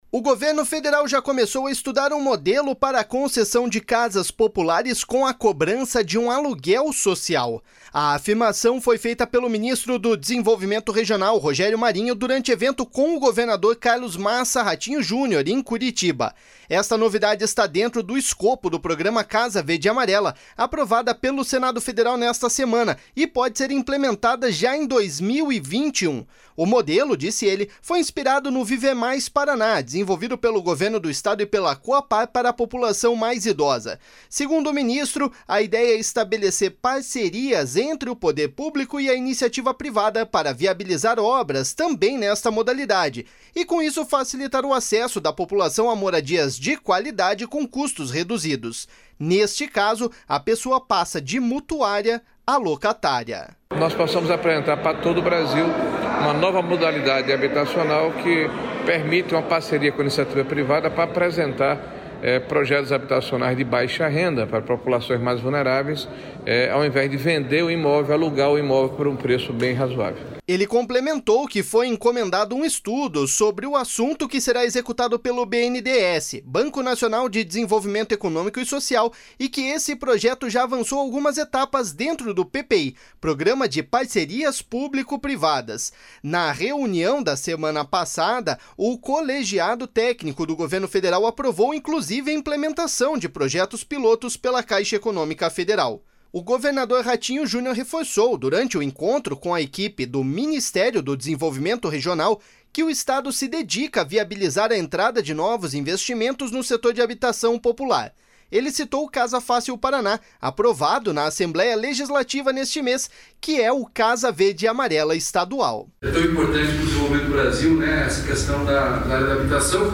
Nesse caso, a pessoa passa de mutuária a locatária.// SONORA ROGÉRIO MARINHO.//
Ele citou o Casa Fácil Paraná, aprovado na Assembleia Legislativa neste mês, que é o Casa Verde e Amarela estadual.// SONORA RATINHO JUNIOR.//